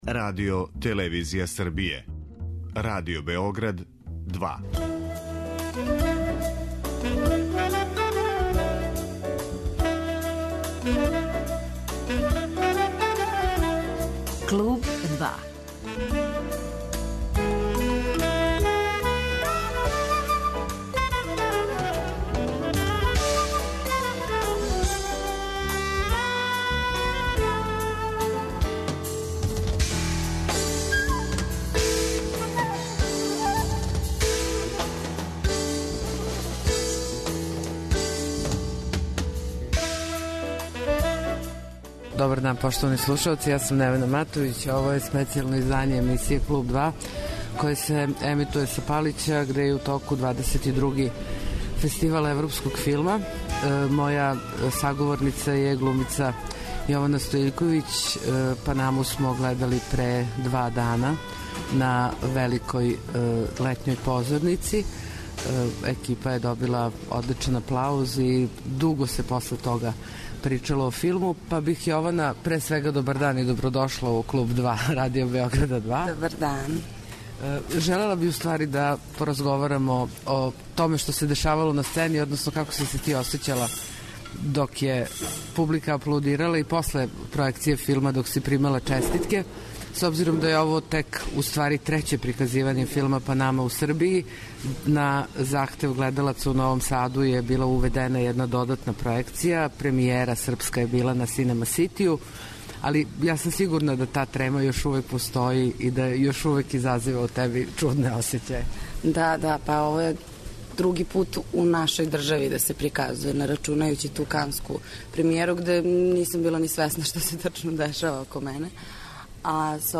Данашње, специјално издање 'Клуба 2' емитује се са Палића, где је у току 22. издање Фестивала европског филма.